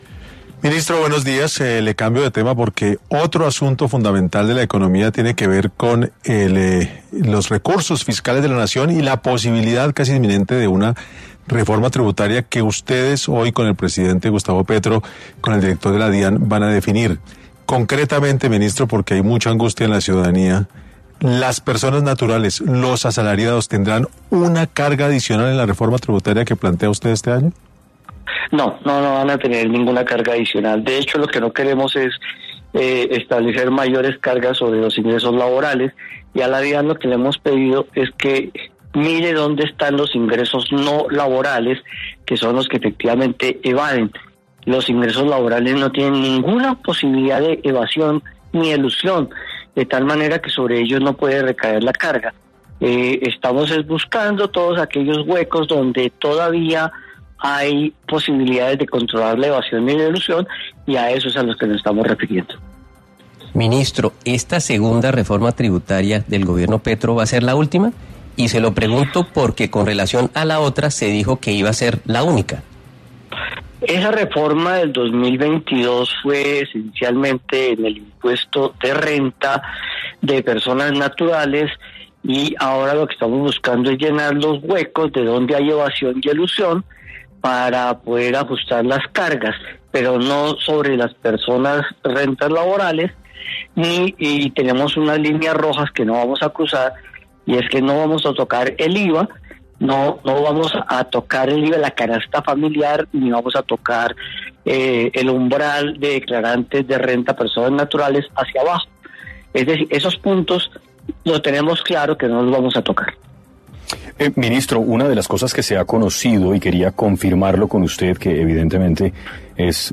El ministro de Hacienda y Crédito Público, Ricardo Bonilla, habló en 6AM sobre las implicaciones de la Reforma Tributaria que quiere implementar este Gobierno.
Ricardo Bonilla, ministro de Hacienda, abordó en los micrófonos de 6AM Hoy por Hoy, la propuesta de Reforma Tributaria que quiere implementar el Gobierno de Gustavo Petro.